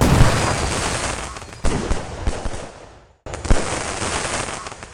crackle.ogg